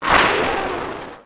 growl3.wav